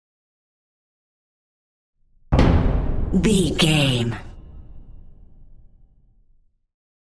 Dramatic Hit Trailer
Sound Effects
Atonal
heavy
intense
dark
aggressive
hits